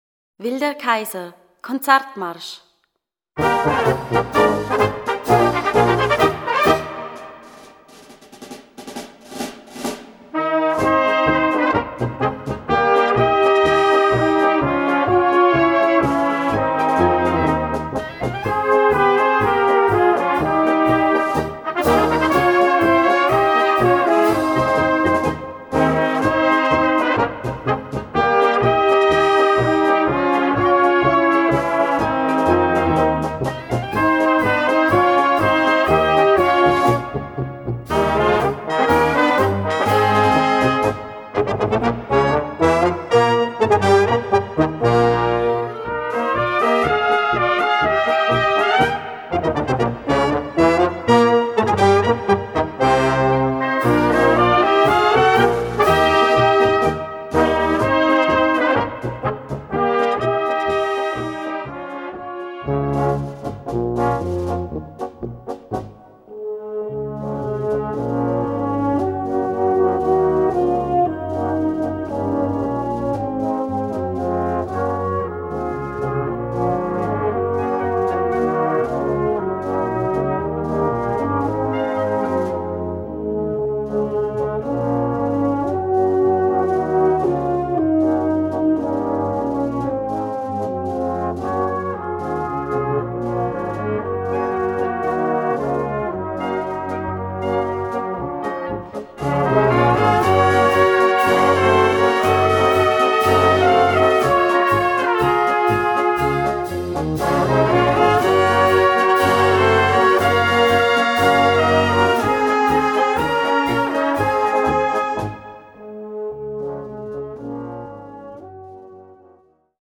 Gattung: Konzertmarsch
Besetzung: Blasorchester